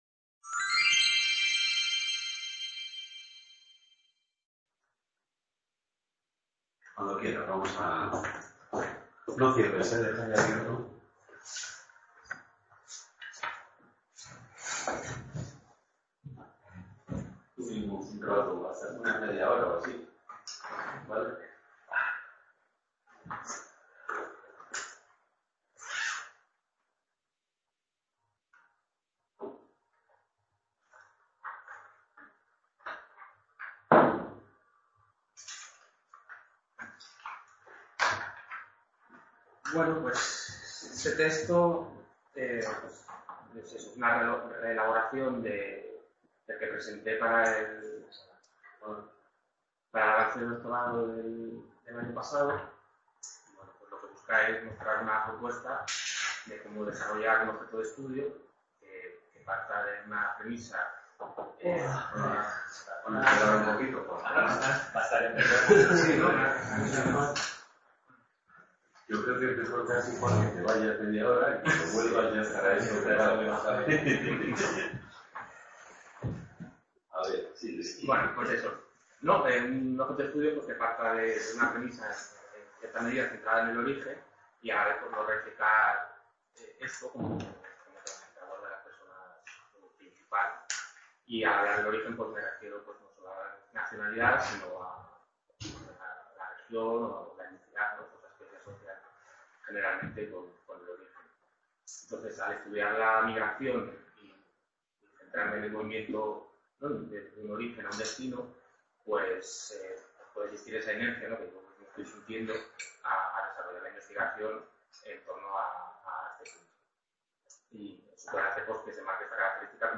Un vistazo etnográfico a lo "bangladesí" en Lavapiés Description Seminario Abierto del Departamento de Antropología Social y Cultural.